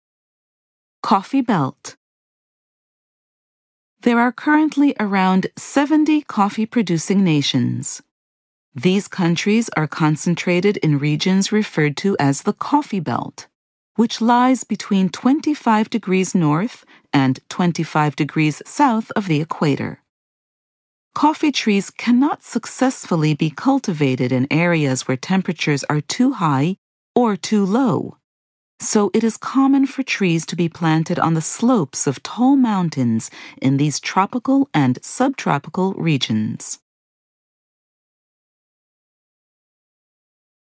Audio exhibition guide